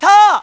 Hiryu's yell before slashing